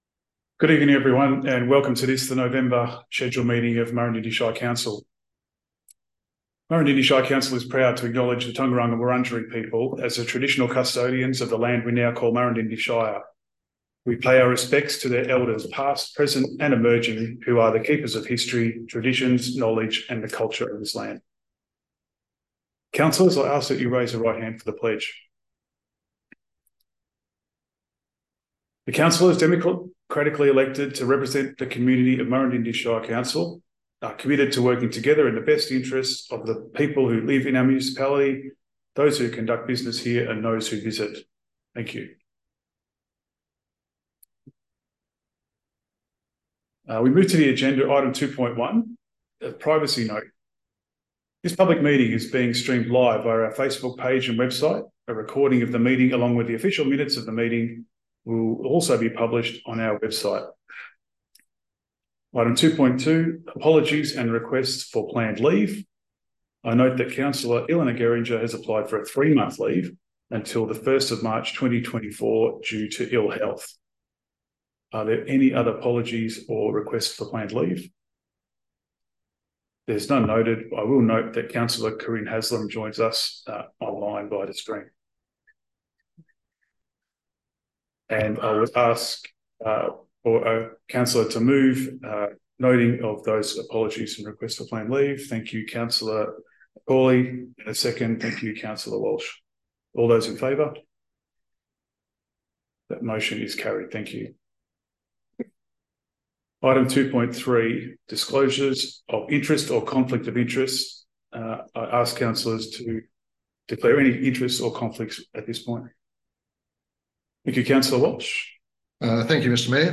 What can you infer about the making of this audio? Location Council Chambers, Yea